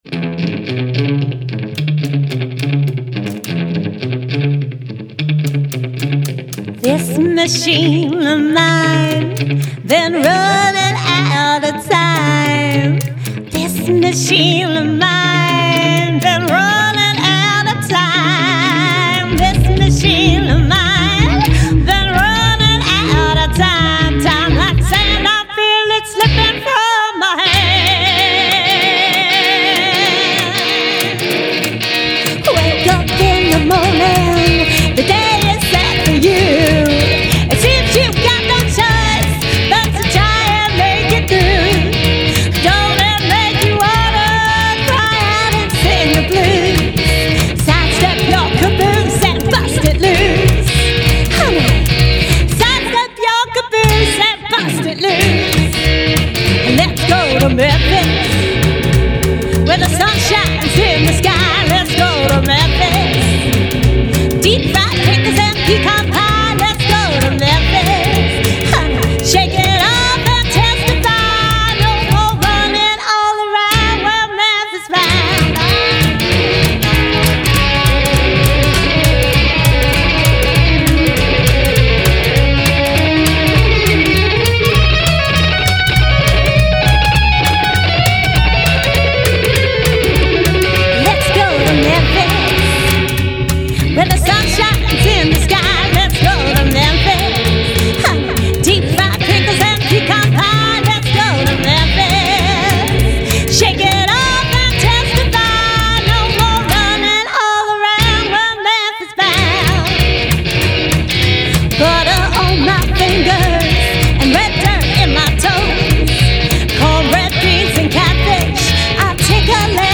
Sun Studios, Memphis